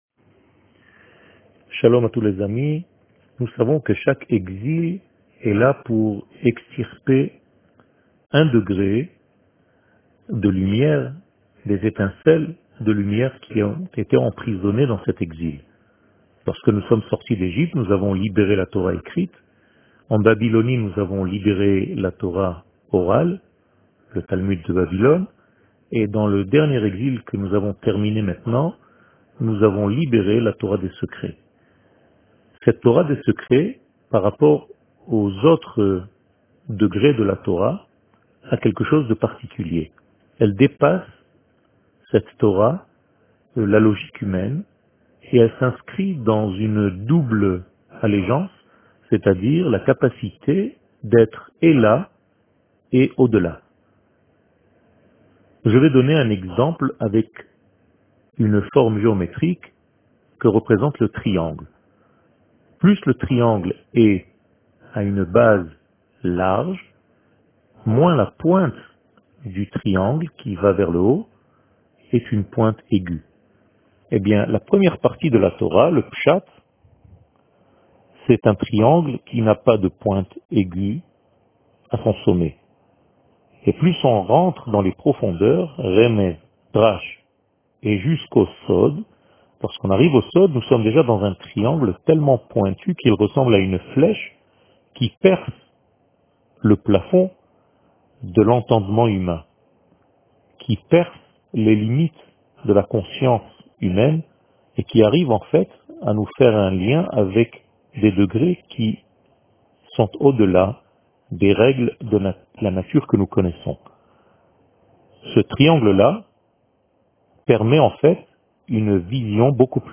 שיעור מ 22 יולי 2020
שיעורים קצרים